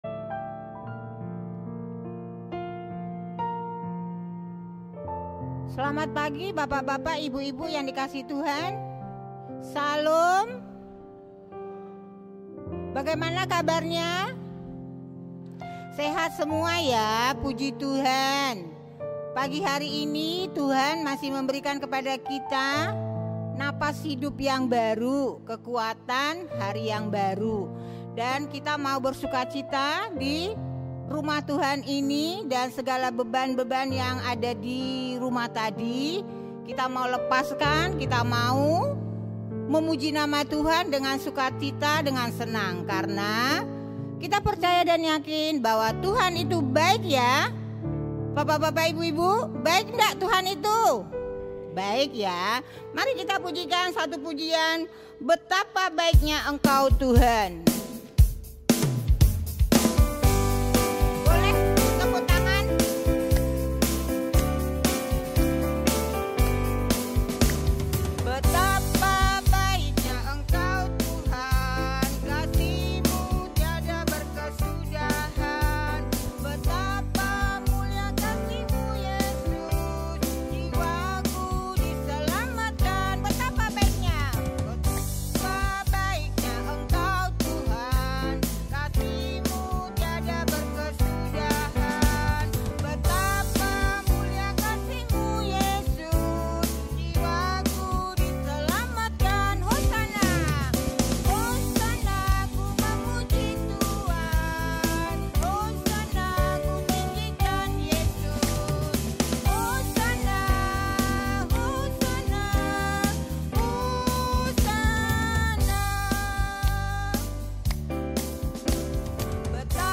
Memuliakan Allah dan diri sendiri – Persekutuan KUL Senior